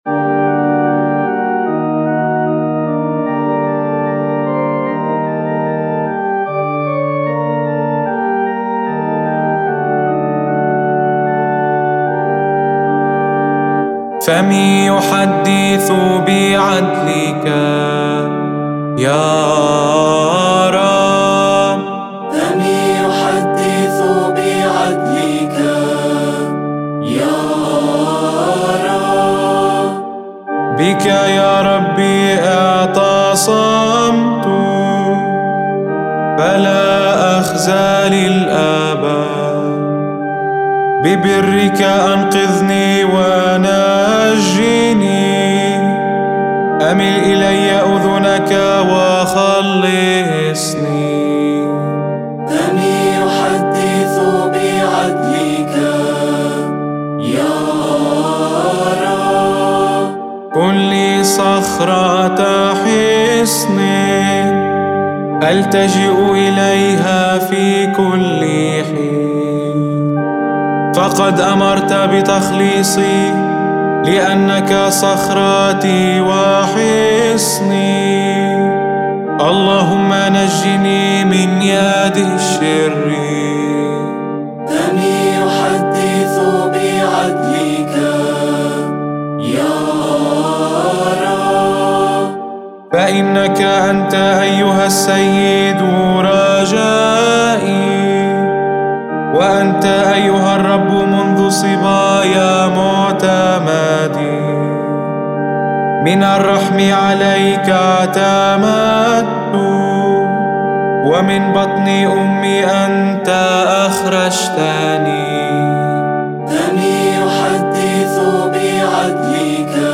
مزمور الردّة ليوم الثلاثاء المقدس (ك. الأسبوع المقدس-ص 130)